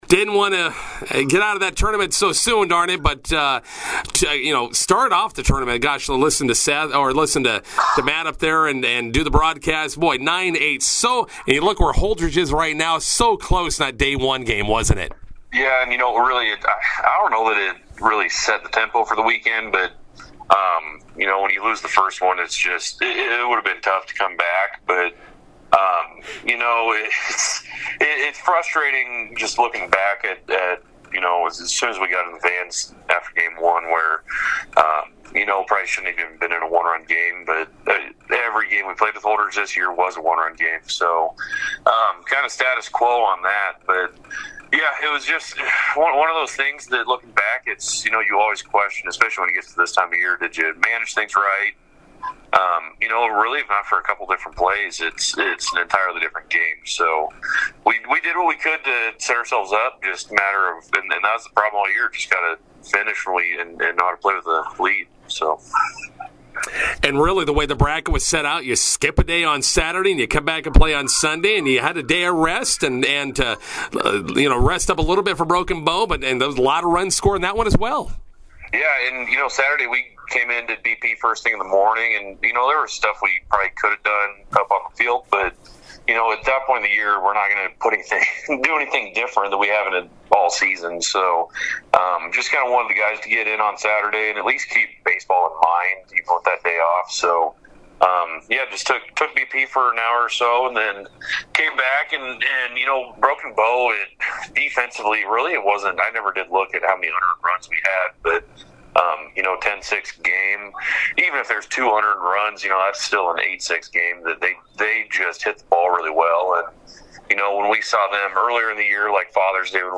INTERVIEW: MNB Seniors wrap up summer season at the Class B6 district tournament.